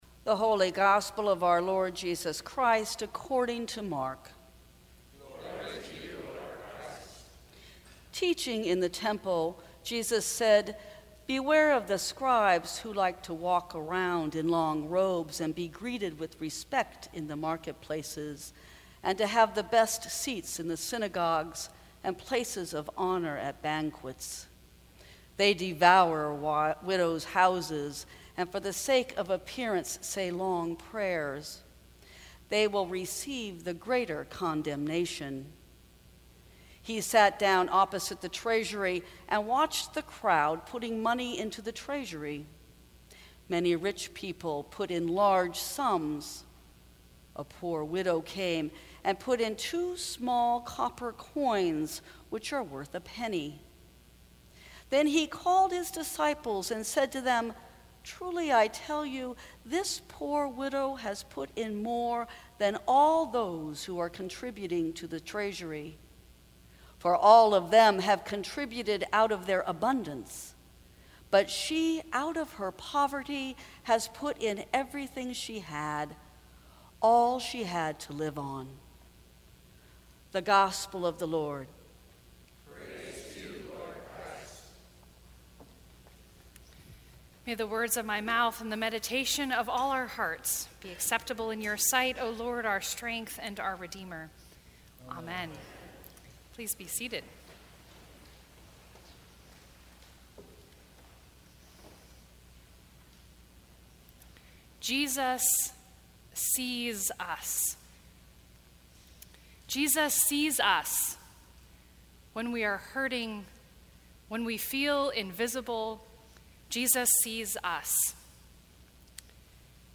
Sermons from St. Cross Episcopal Church Love in Times Like These Nov 14 2018 | 00:14:47 1x 00:00 / 00:14:47 Subscribe Share Apple Podcasts Spotify Overcast RSS Feed Share Link Embed